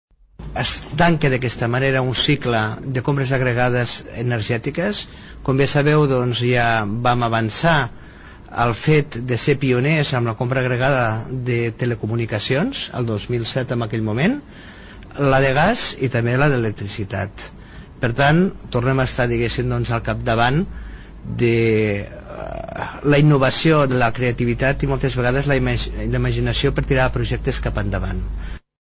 Ho explica el president del Consell Comarcal del Maresme, Miquel Àngel Martínez.